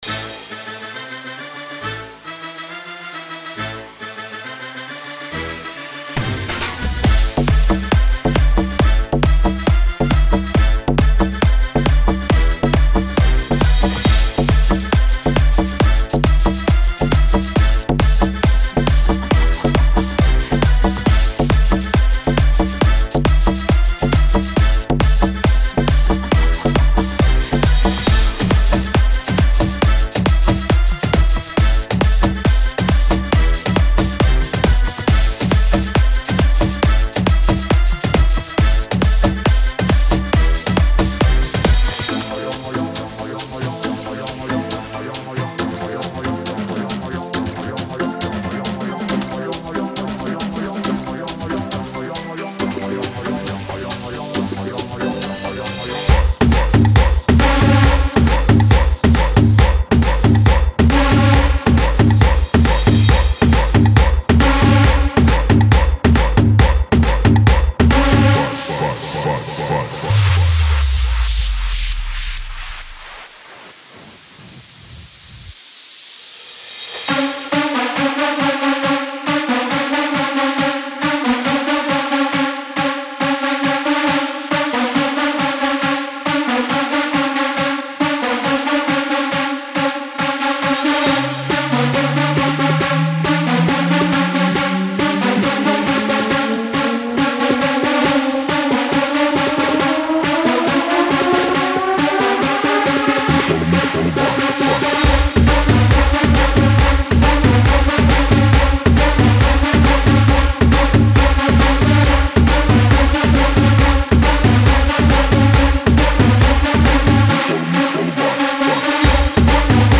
GENERO: CIRCUIT / ELECTRO POP